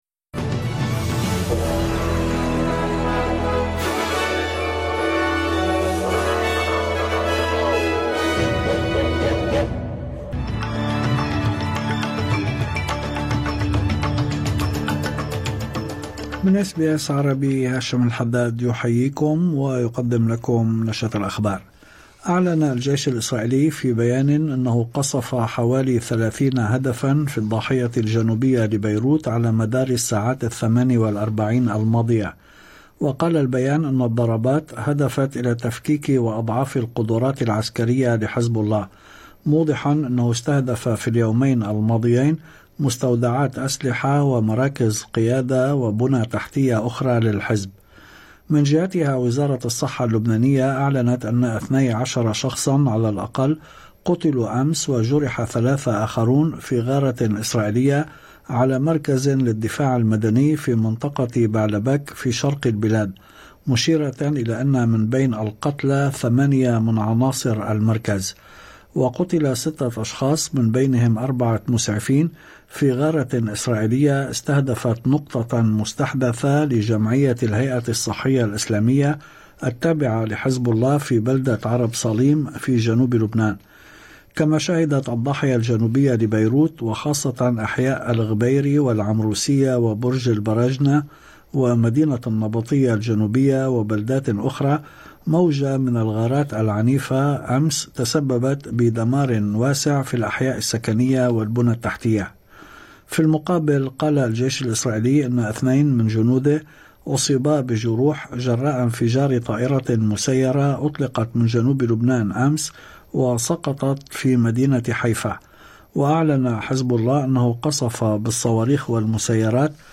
نشرة أخبار الظهيرة 15/11/2024